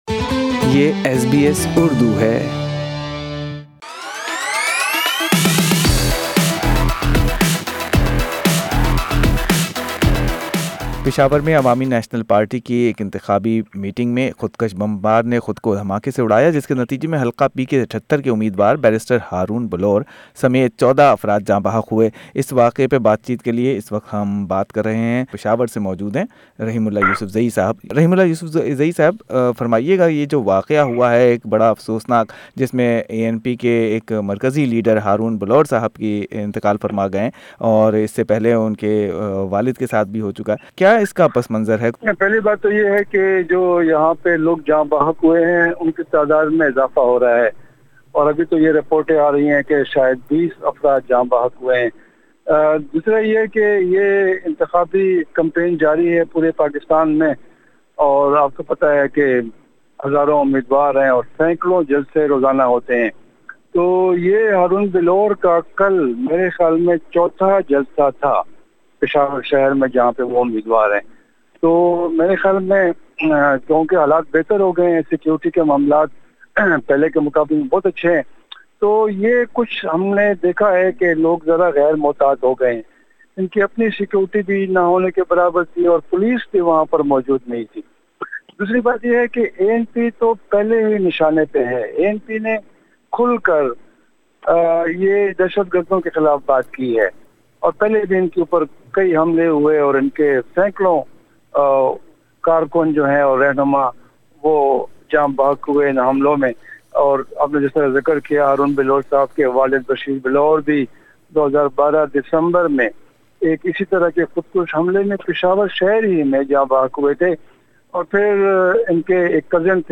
Senior analyst and journalist Rahimullah Yusufzai told SBS Urdu that Bilour family is on target of extremist and his father was also assassinated.